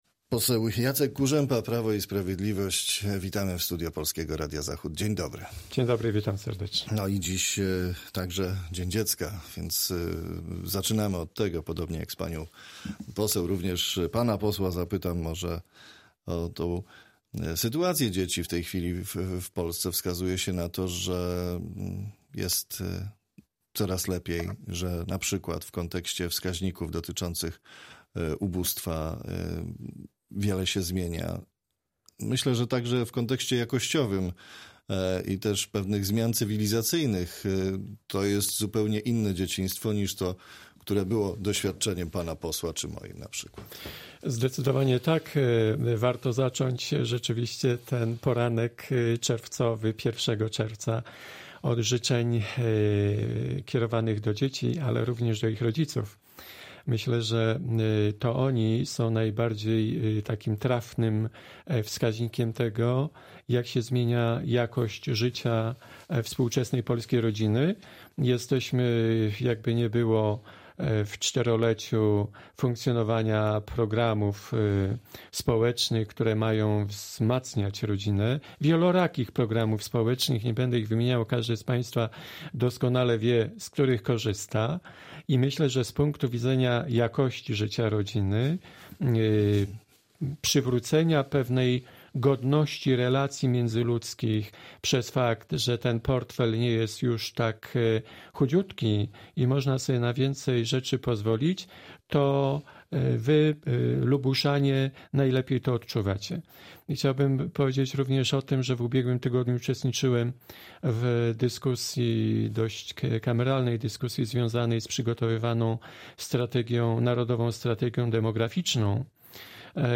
Z posłem PiS rozmawia